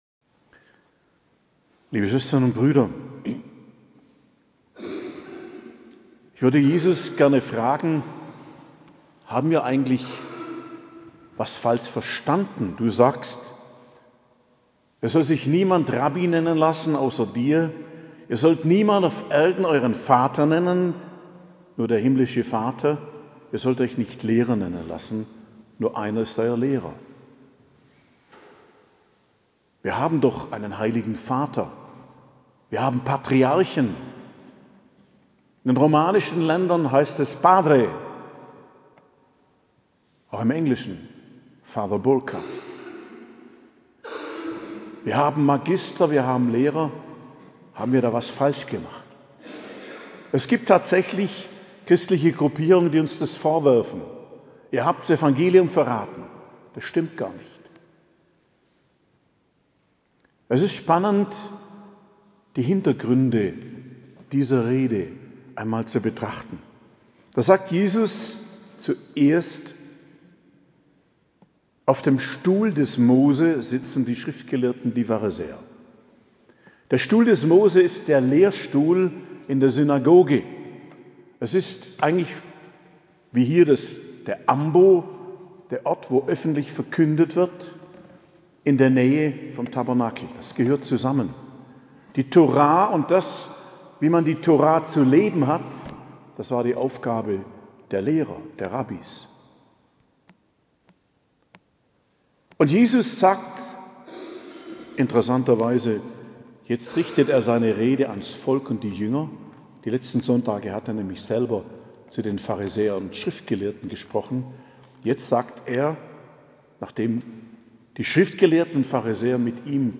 Predigt zum 31.